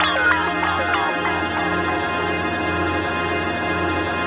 ATMO-PAD 01